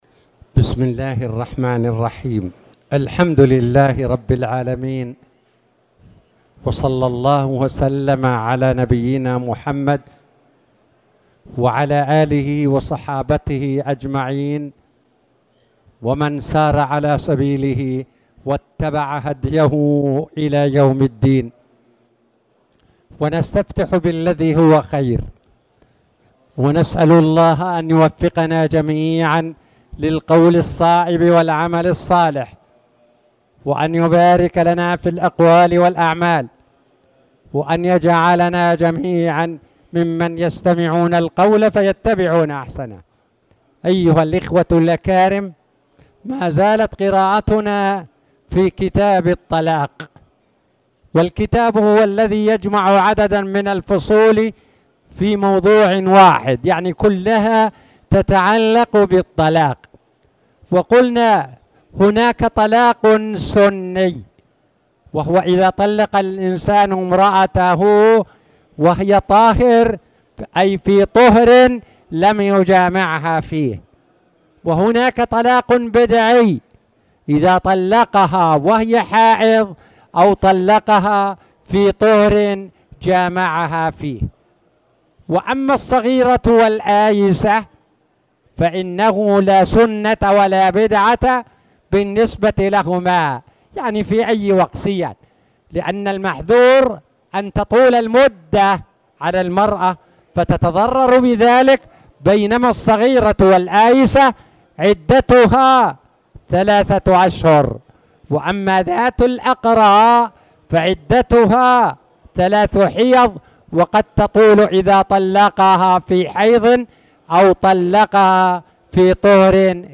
تاريخ النشر ٢٠ جمادى الآخرة ١٤٣٨ هـ المكان: المسجد الحرام الشيخ